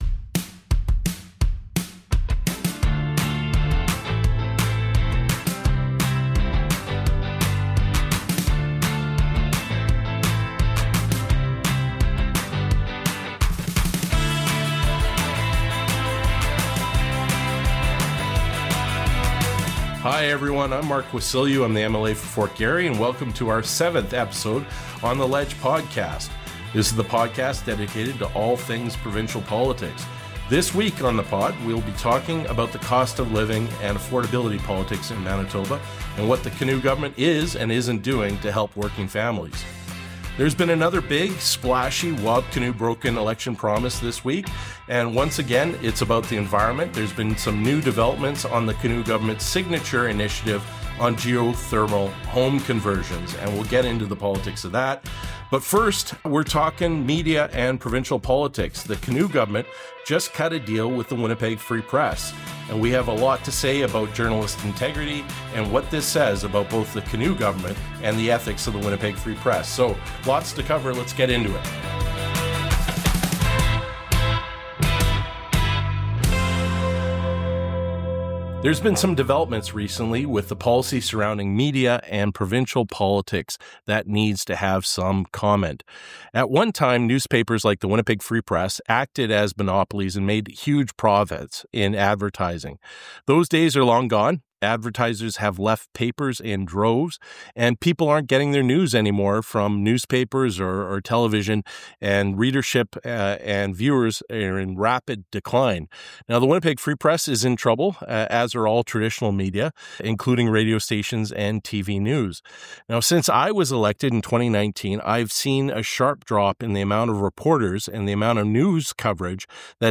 Part 2 – An interview